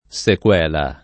sequela [ S ek U$ la ] s. f.